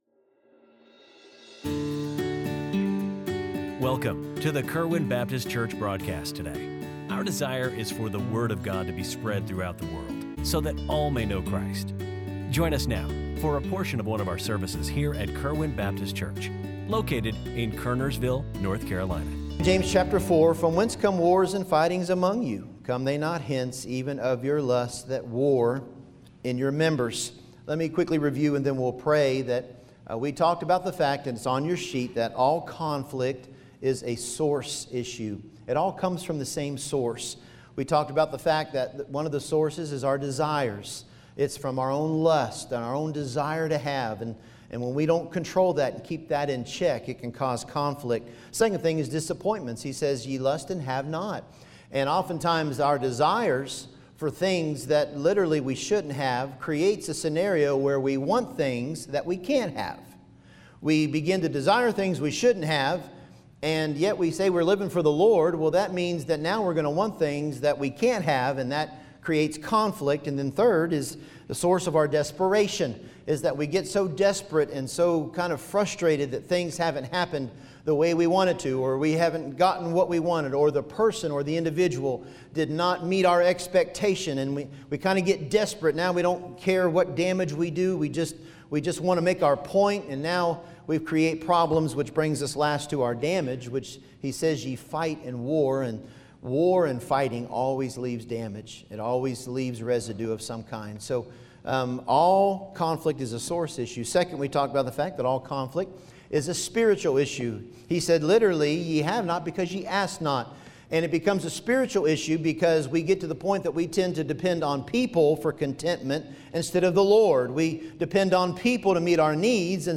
Kerwin Baptist Church Daily Sermon Broadcast